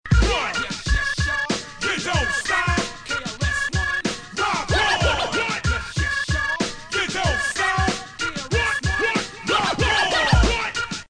Boucle